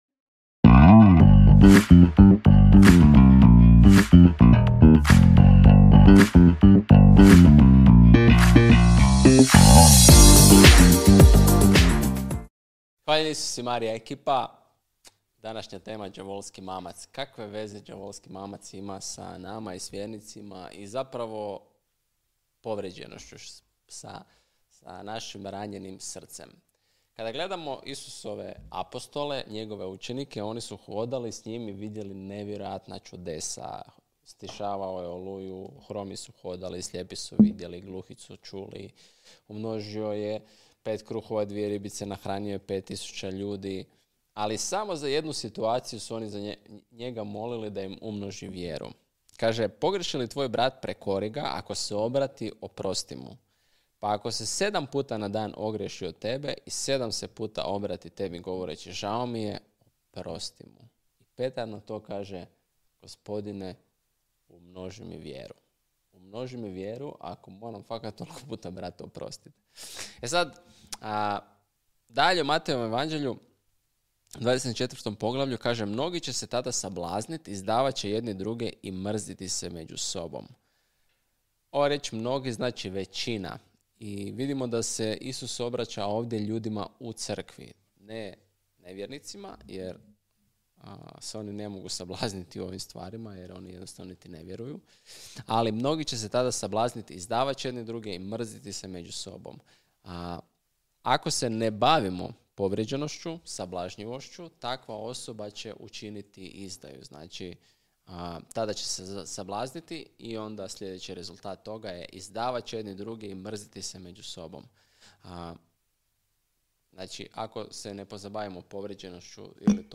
Poslušaj ovaj zanimljiv razgovor, sigurno će ti pomoći!